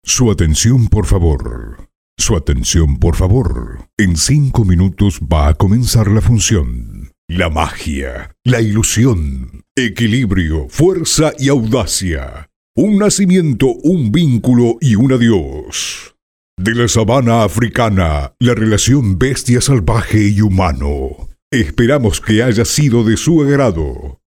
voice over
Demo-Off.mp3